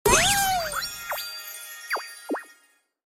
Nada notifikasi Emote Mobile Legends LOL
Kategori: Nada dering
nada-notifikasi-emote-mobile-legends-lol-id-www_tiengdong_com.mp3